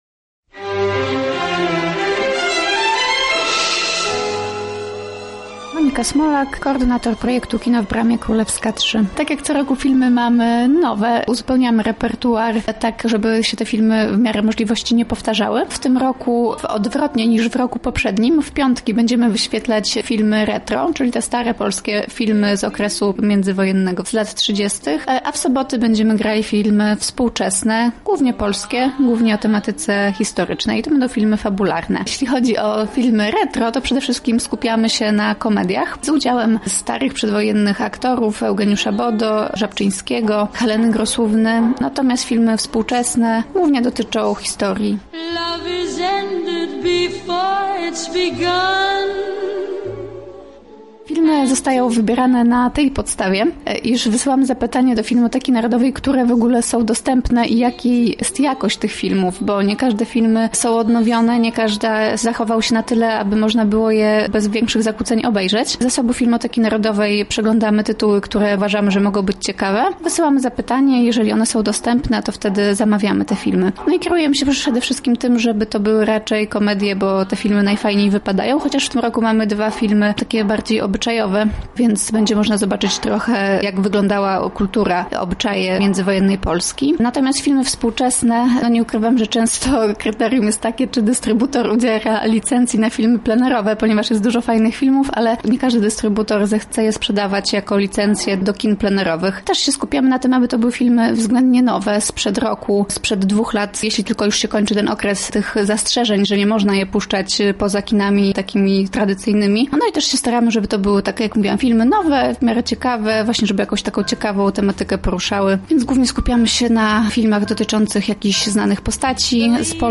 rozmawiała nasza reporterka